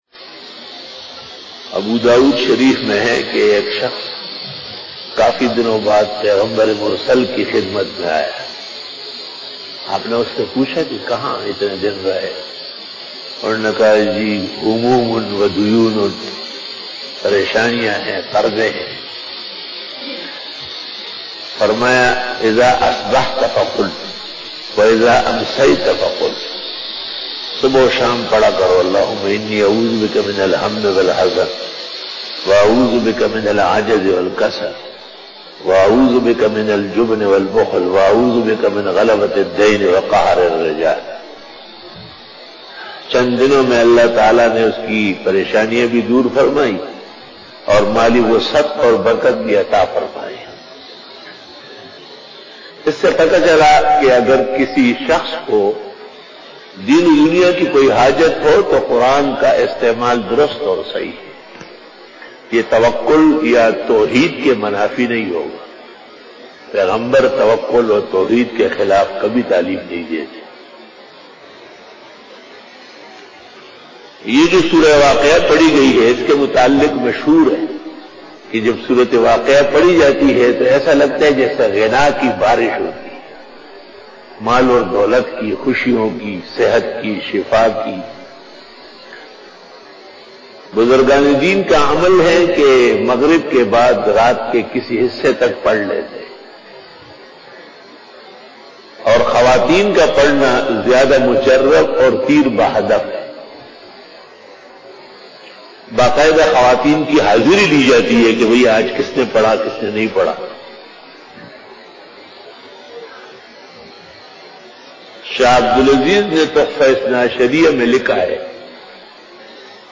After Namaz Bayan
بیان بعد نماز فجر